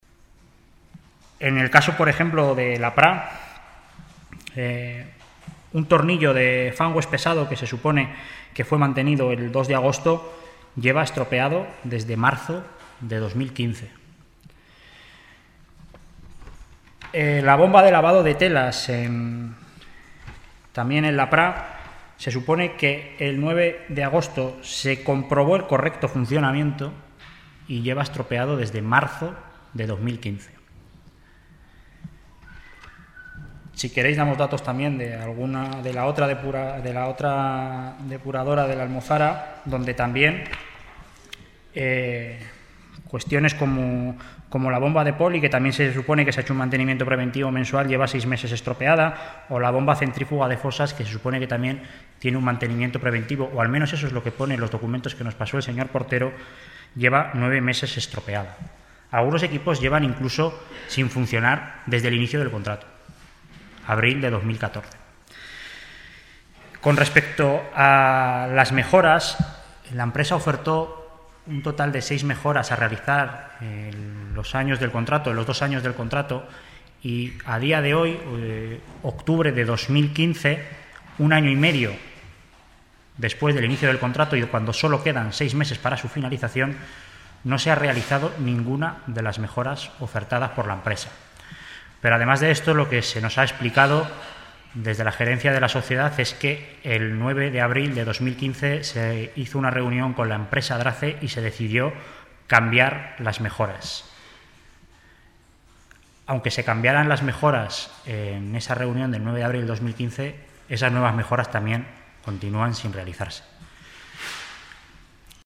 El Consejero de Servicios Públicos y Personal, Alberto Cubero, y la Concejal  Delegada de Medio Ambiente y Movilidad, Teresa Artigas, han explicado en rueda de Prensa que dichas irregularidades ya habían sido denunciadas en el seno del Observatorio de la Contratación en la legislatura pasada.